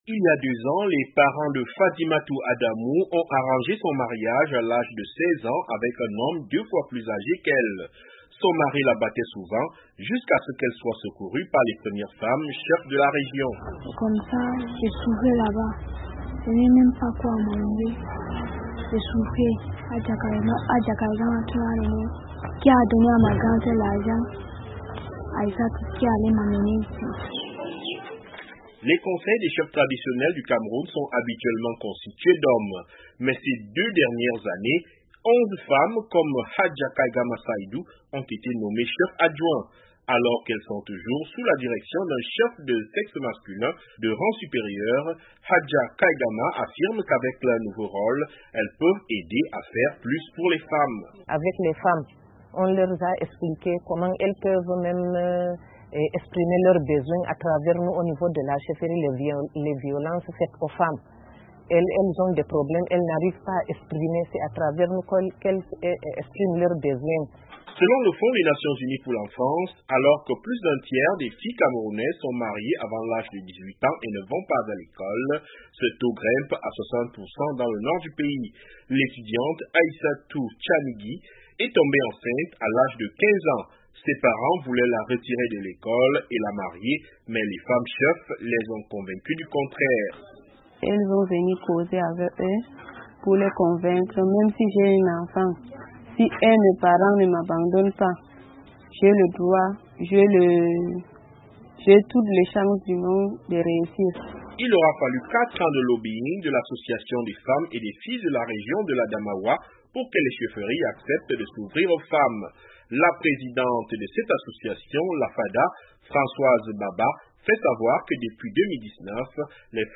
Certaines sont pionnières dans la lutte contre le mariage précoce, dans une région où la majorité des filles sont mariées avant l'âge de 18 ans. Reportage